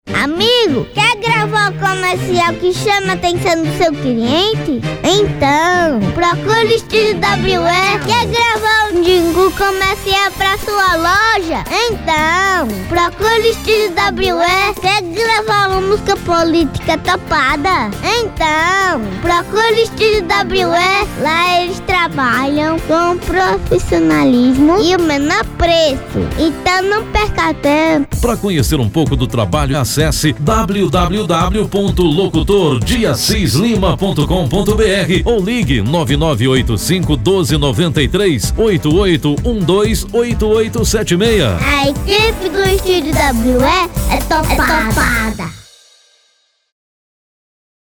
Espanhol - Castelhano (Espanha)